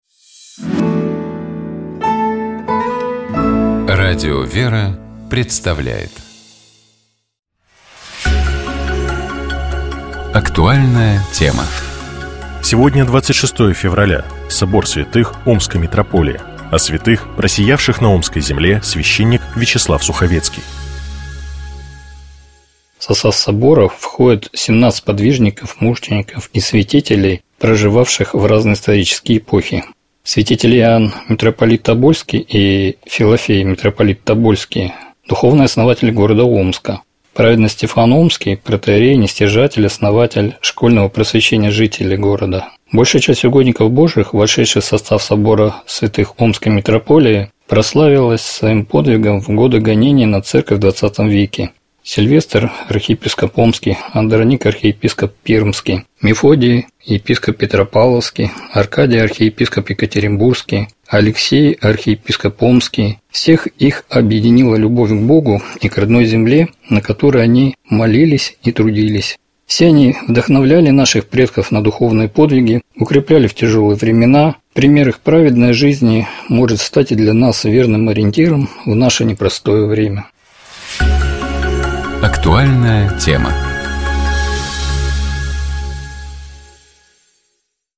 О святых, просиявших на омской земле, — священник